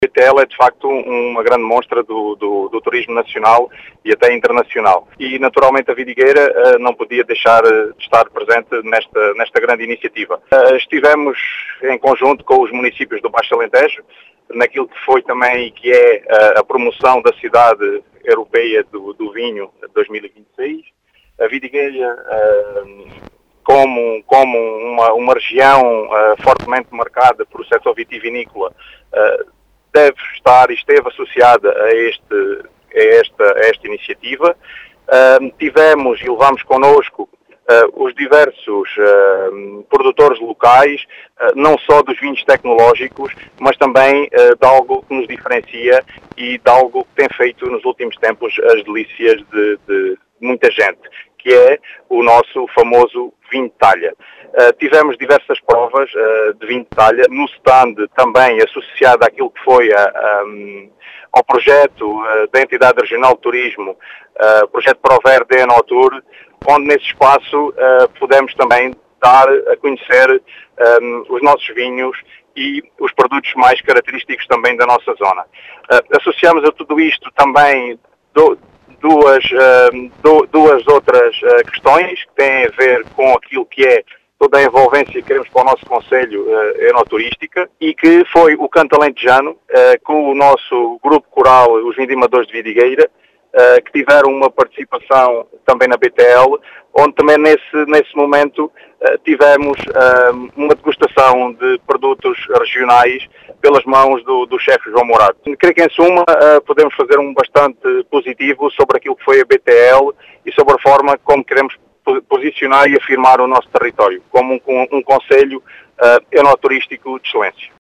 As explicações são do presidente da Câmara Municipal de Vidigueira, Ricardo Bonito, que fez um “balanço positivo” desta participação.